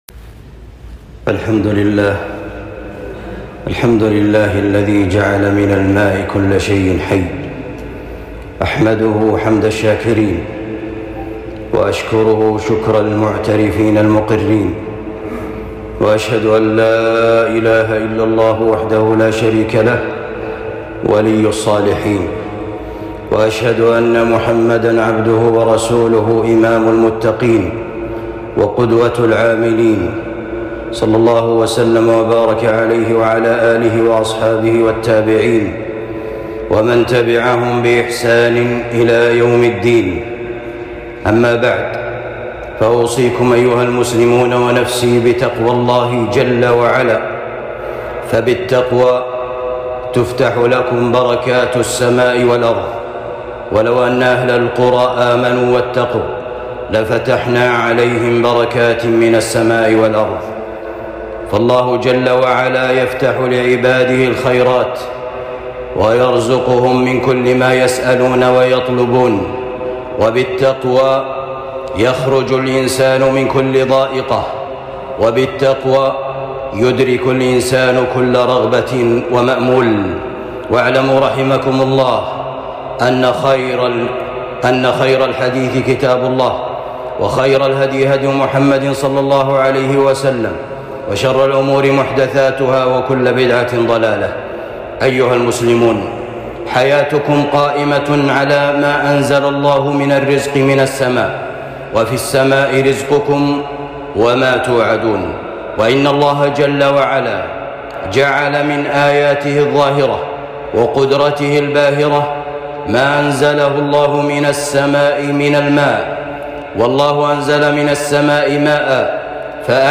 خطبة بعنوان نعمة الماء والحث على الترشيد في استهلاكها